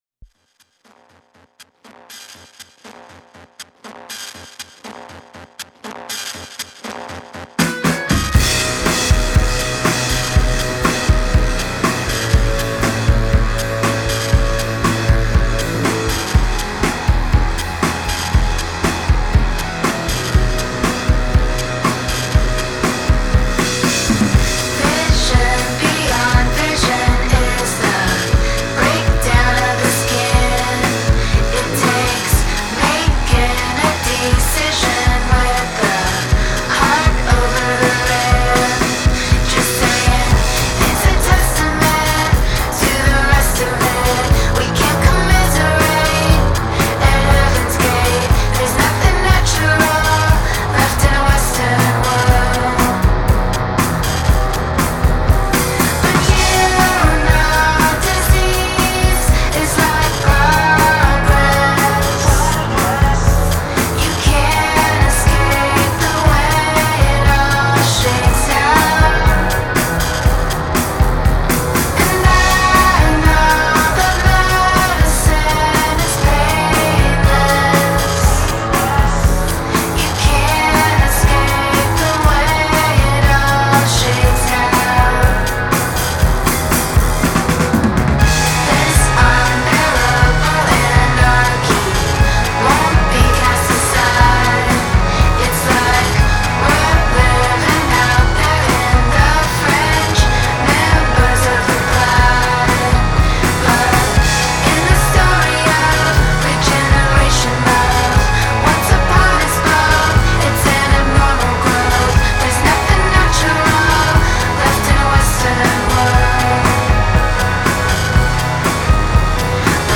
bright, buzzy, unsettling
indie rock band
two female lead singers